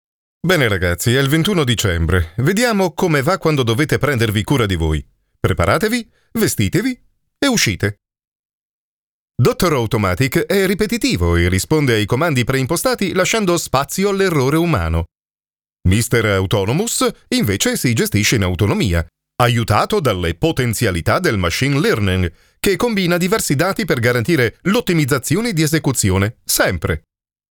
E-learning
Posso descrever minha voz como uma voz masculina de "meia-idade", profunda, calorosa, comunicativa, para documentários ou onde o sentimento é necessário.
Barítono